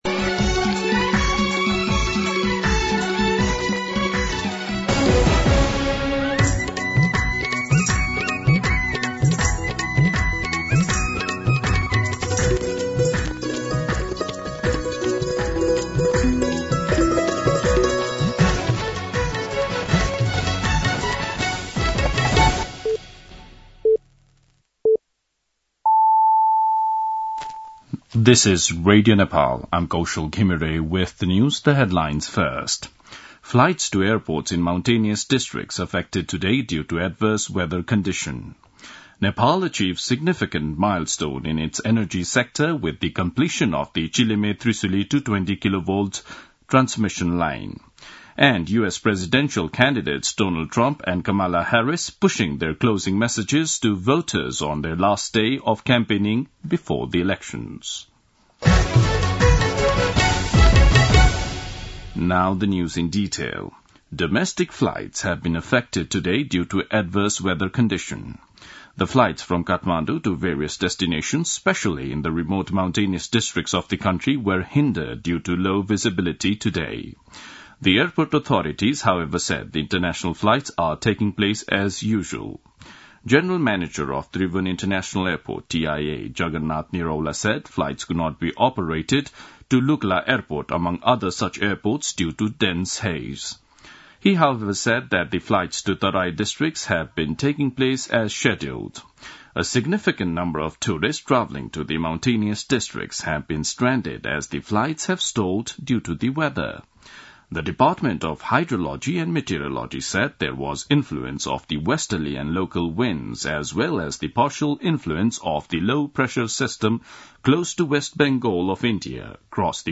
दिउँसो २ बजेको अङ्ग्रेजी समाचार : २० कार्तिक , २०८१
2-pm-English-News-19.mp3